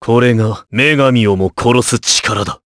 Clause_ice-Vox_Skill7_jp.wav